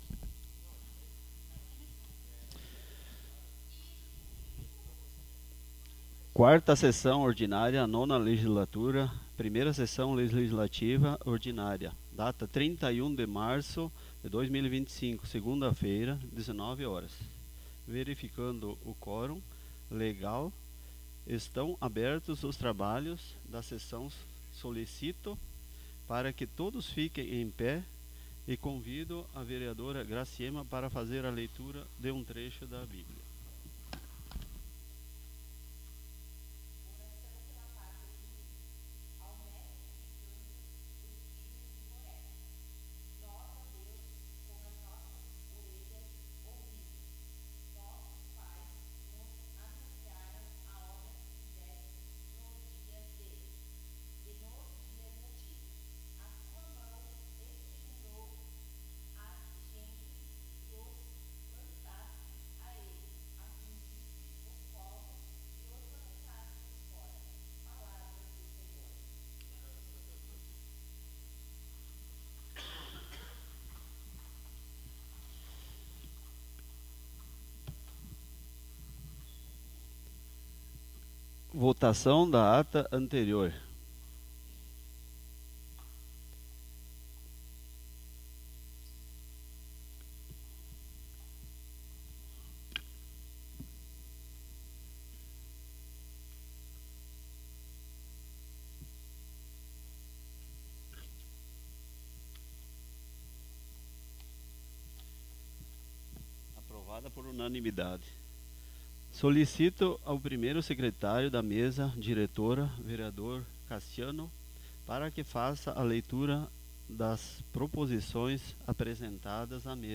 Audio da 4ª Sessão Ordinária 31.03.25